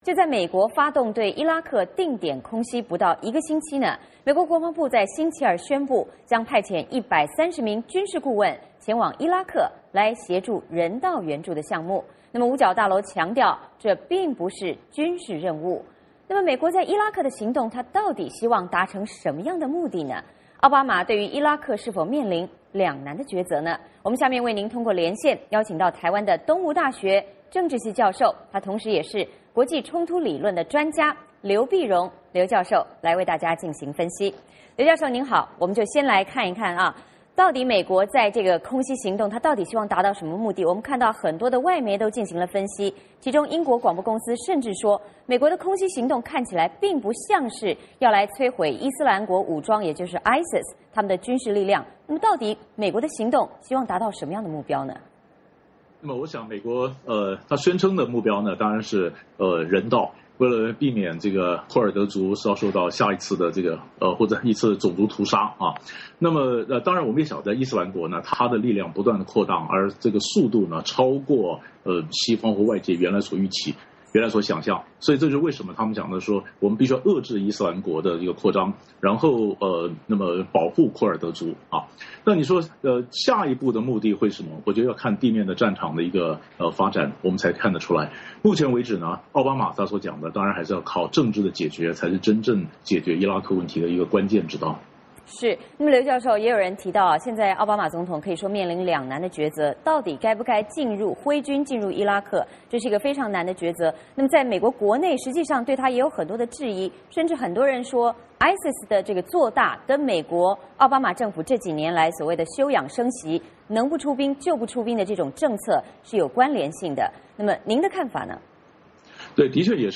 VOA连线：美国空袭伊拉克的目的何在？